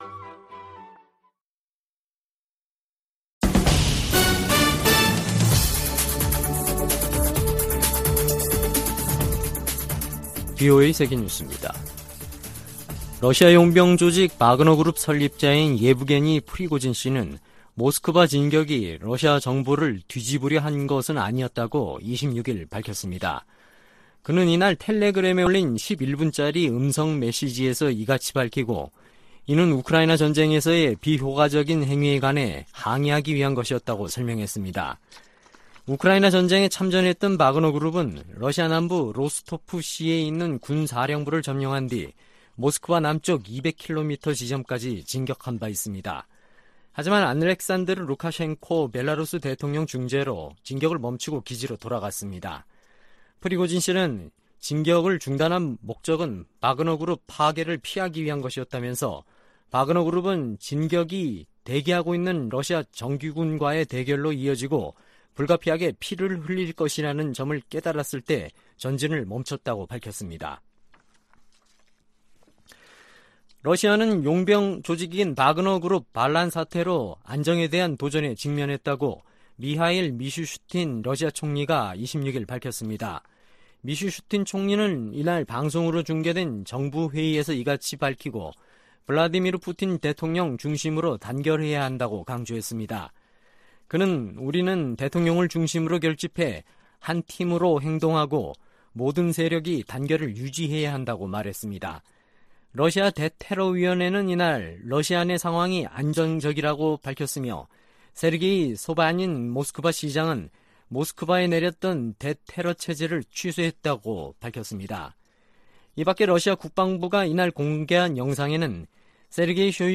VOA 한국어 아침 뉴스 프로그램 '워싱턴 뉴스 광장' 2023년 6월 27일 방송입니다. 북한 동창리 서해위성발사장의 새 로켓 발사대 주변에서 새로운 움직임이 포착돼 새 발사와의 연관성이 주목됩니다. 미국은 중국에 대북 영향력을 행사할 것을 지속적으로 촉구하고 있다고 백악관이 밝혔습니다. 국무부는 북한의 식량난이 대북 제재 때문이라는 러시아 대사의 주장에 대해 북한 정권의 책임을 다른 곳으로 돌리려는 시도라고 비판했습니다.